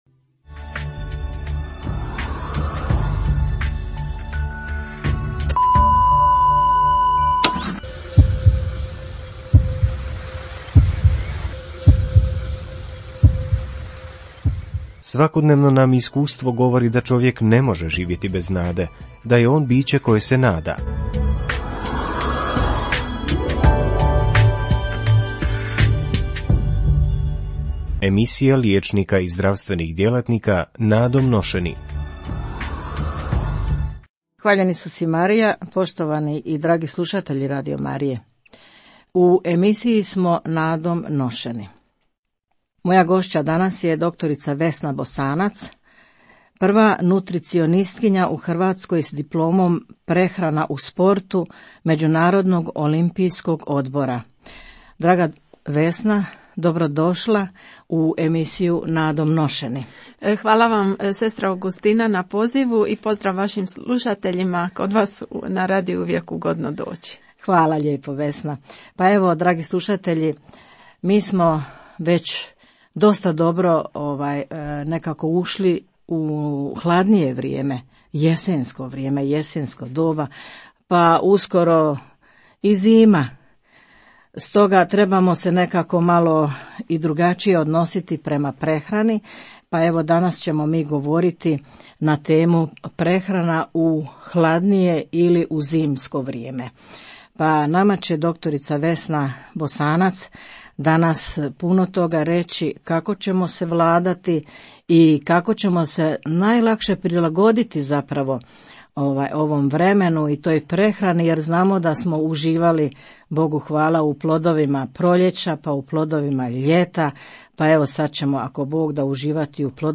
tema: Prehrana u jesensko vrijeme; gošća